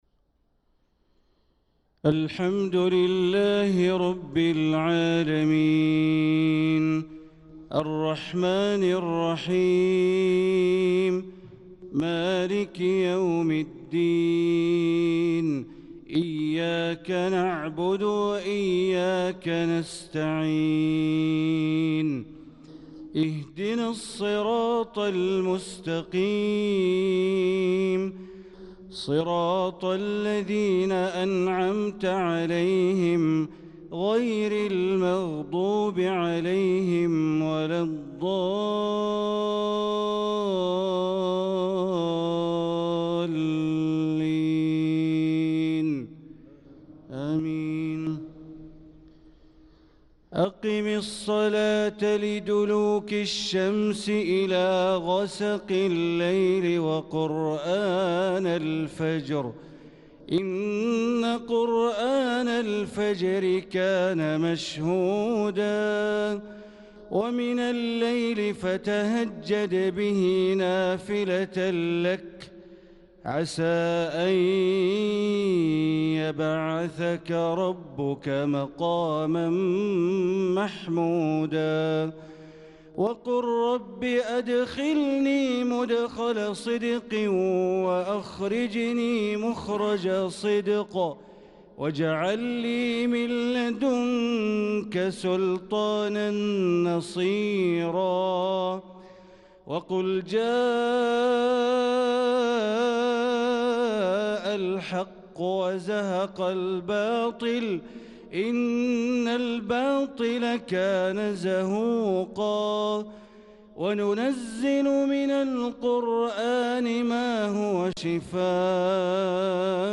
صلاة الفجر للقارئ بندر بليلة 15 ذو الحجة 1445 هـ
تِلَاوَات الْحَرَمَيْن .